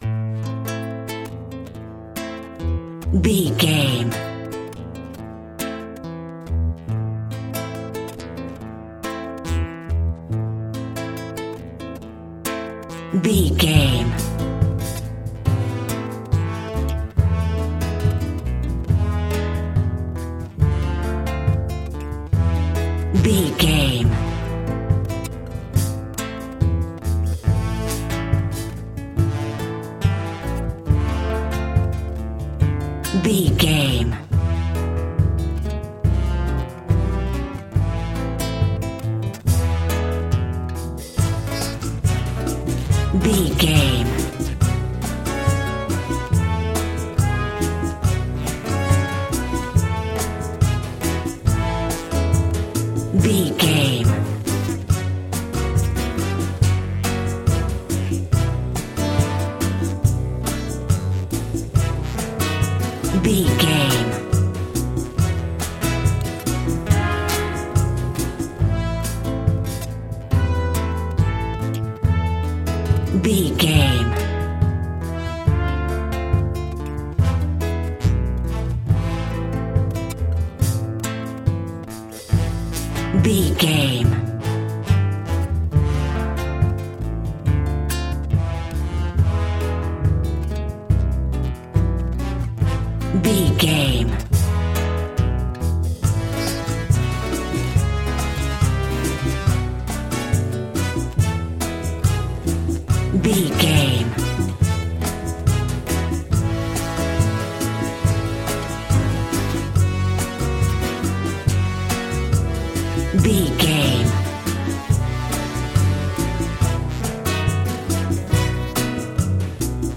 Aeolian/Minor
maracas
percussion spanish guitar